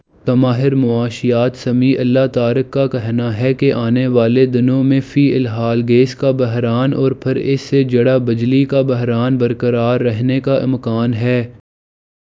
deepfake_detection_dataset_urdu / Spoofed_TTS /Speaker_06 /270.wav